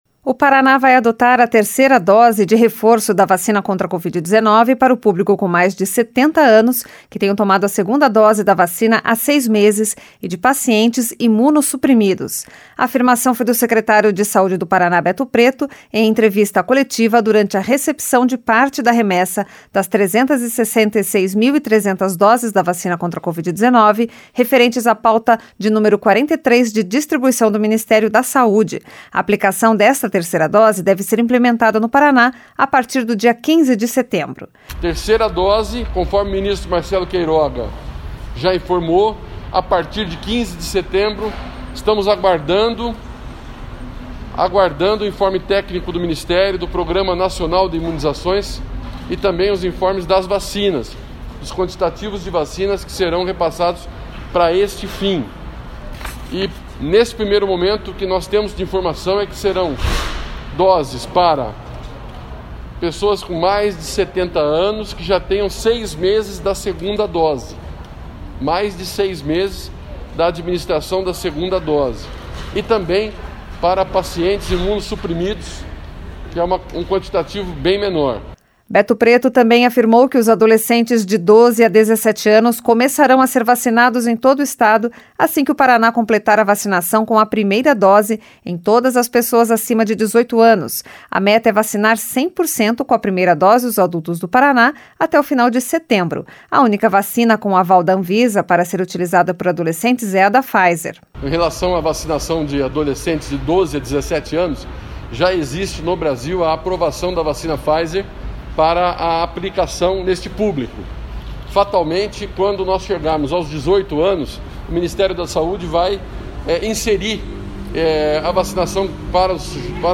A afirmação é do secretário de Saúde do Paraná, Beto Preto, em entrevista coletiva, durante a recepção de parte da remessa das 366.300 doses da vacina contra a Covid-19 referentes à pauta 43 de distribuição do Ministério da Saúde.